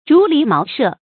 竹籬茅舍 注音： ㄓㄨˊ ㄌㄧˊ ㄇㄠˊ ㄕㄜˋ 讀音讀法： 意思解釋： 用竹子圍的籬笆，茅草蓋頂的房屋。